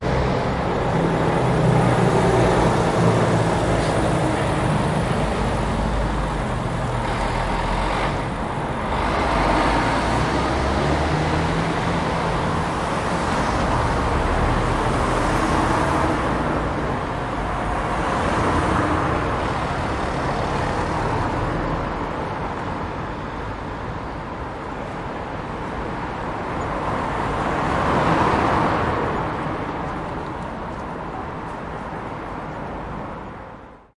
街道噪音
描述：在道路交叉附近的俄罗斯城市的街道噪音。还有一个高速汽车的声音。
标签： 街道的噪音 交通 街道 汽车 现场录音 sportcar 噪音 城市
声道立体声